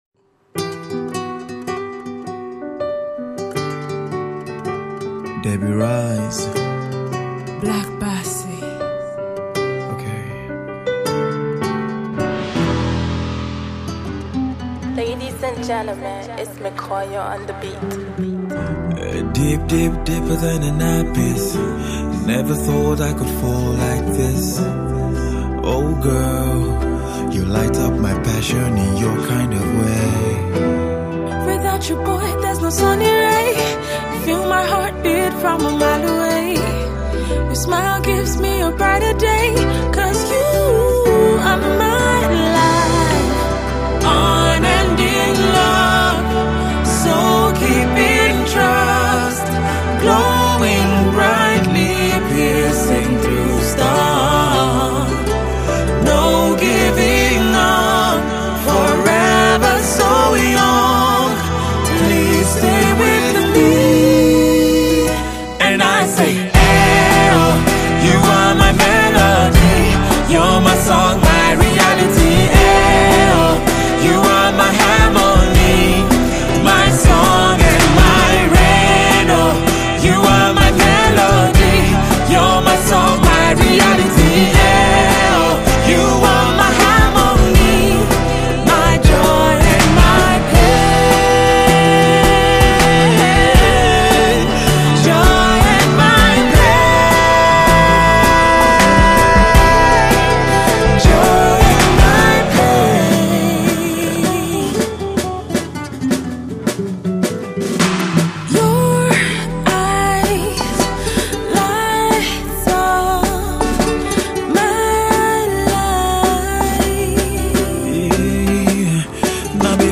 slow-tempo, feel-good tune